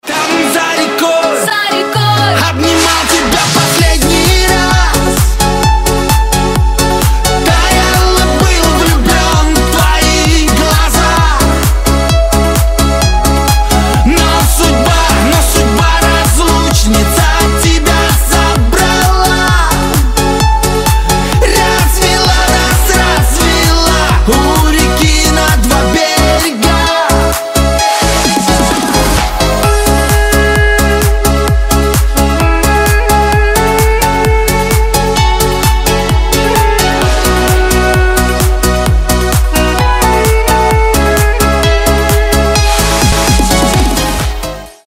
Рок рингтоны , Рингтоны шансон